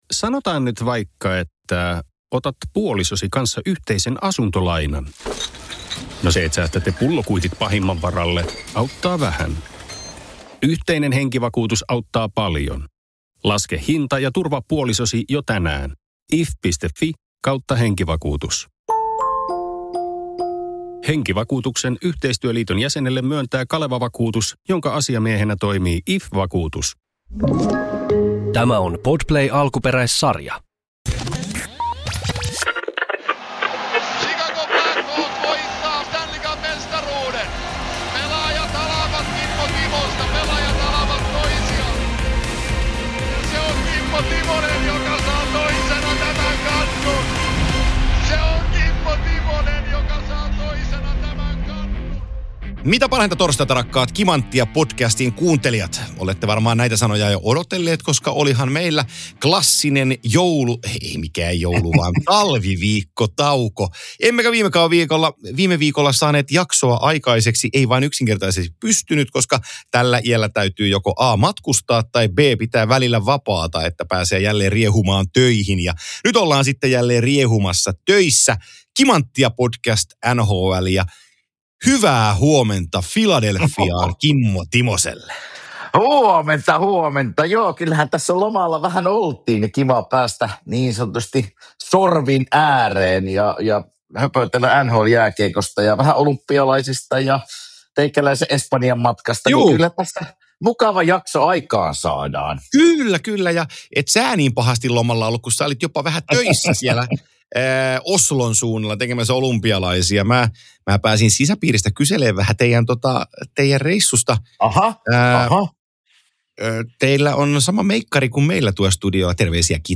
Kimanttia kaksikko kertoo siirtoihin liittyvät tietonsa Kimanttia yhteisölle, sekä tekee todella ison ”hot take” veikkauksen yhdestä NHL:n tähtipelaajan mahdollisesta kaupasta.